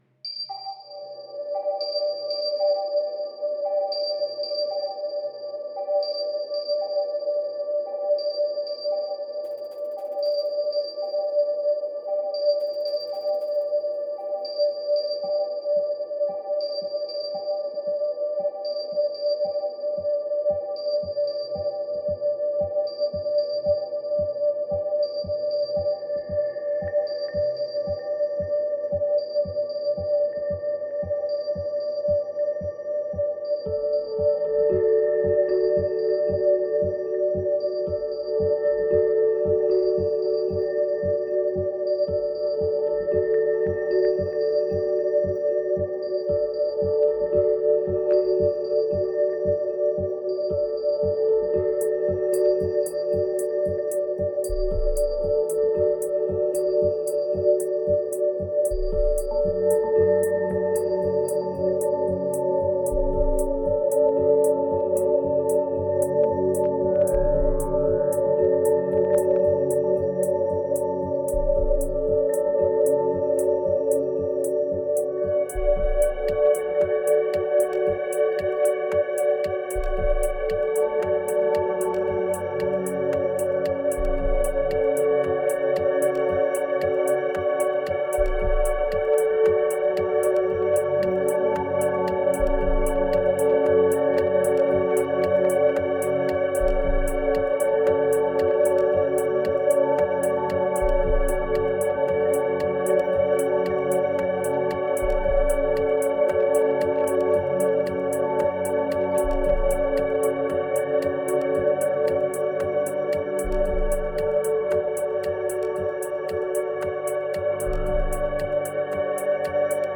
2278📈 - 72%🤔 - 57BPM🔊 - 2015-03-14📅 - 444🌟